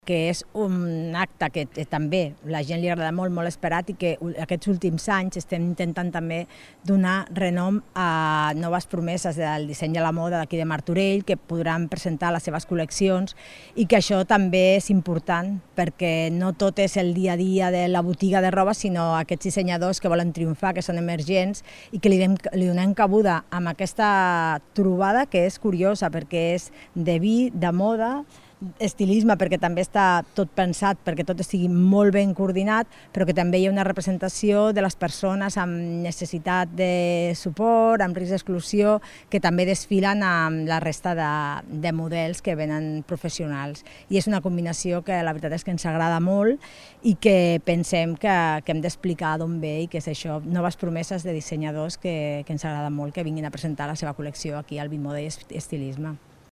Rosa Cadenas, regidora de Promoció Econòmica